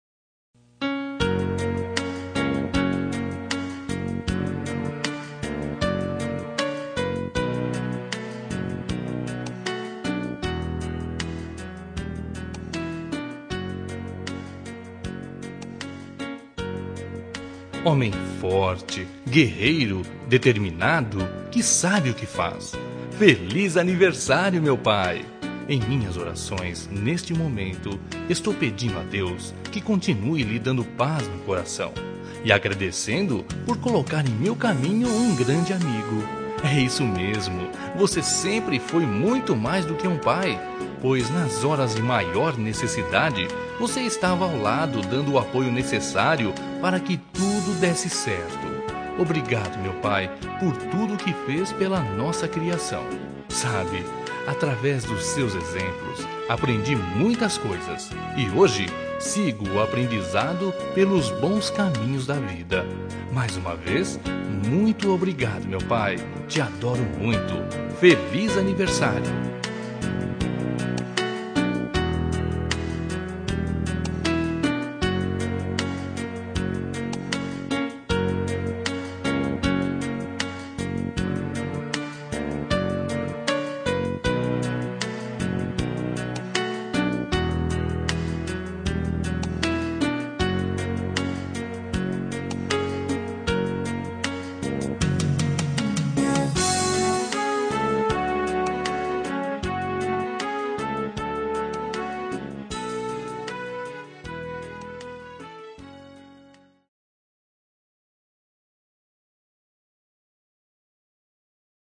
Telemensagem de Aniversário de Pai – Voz Masculina – Cód: 1487